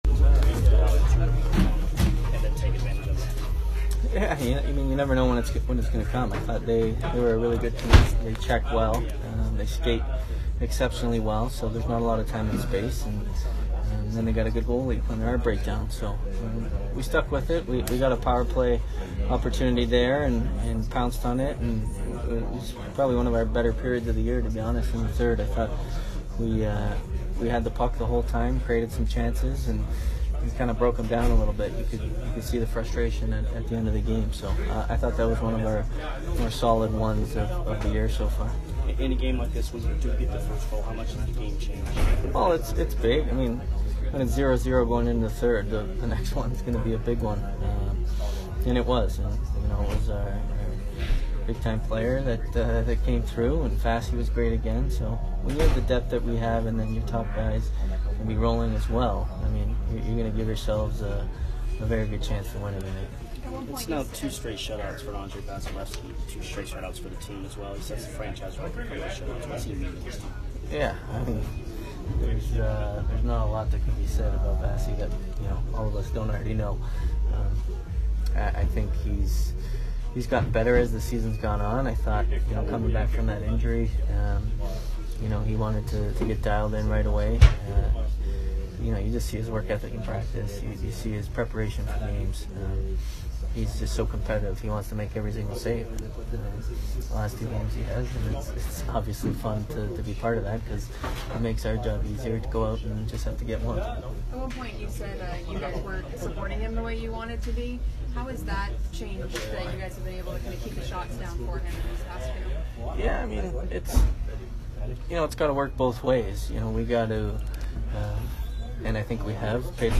Steven Stamkos post-game 2/16